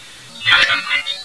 This next EVP was taken in the basement of the home.
For whatever reason....the spirit / ghost voice seems to be whispering...."Look behind you!"